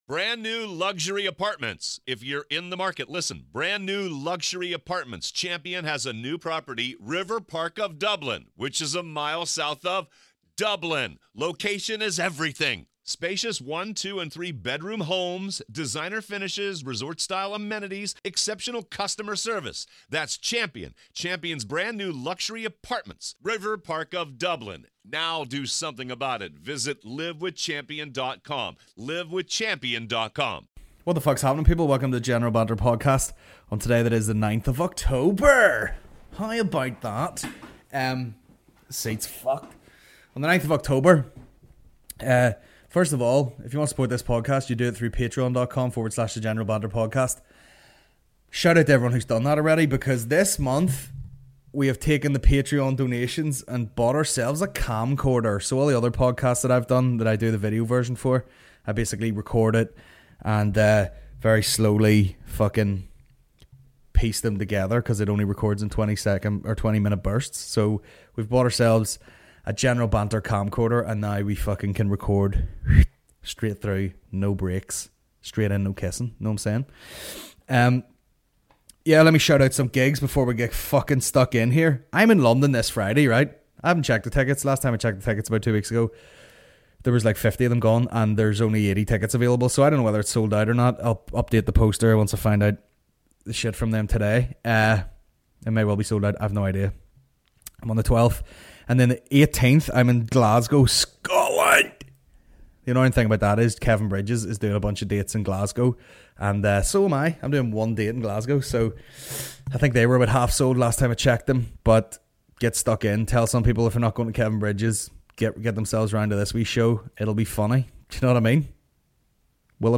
Tuesday 9th October 2018 General Banter Podcast General Banter Podcast Comedy 4.8 • 1.1K Ratings 🗓 9 October 2018 ⏱ 55 minutes 🔗 Recording | iTunes | RSS 🧾 Download transcript Summary This week - Just Do It.